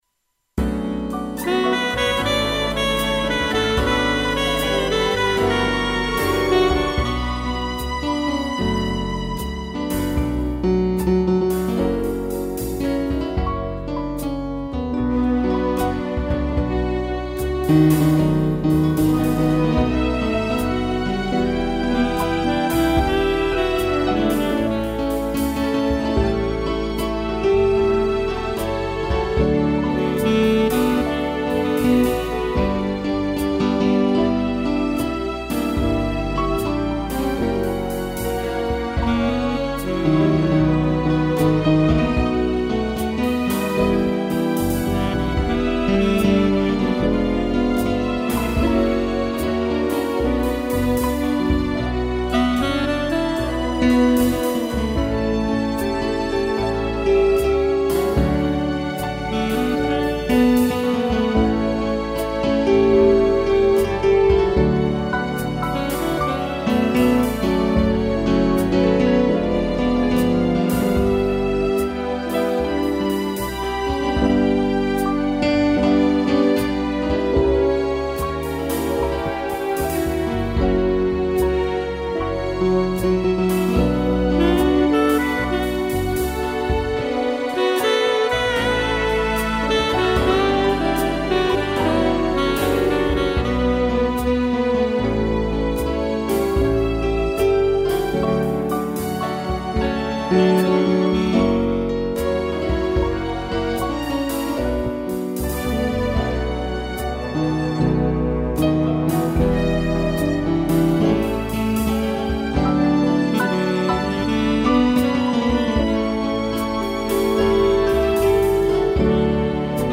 piano, violino, cello e sax
(instrumental)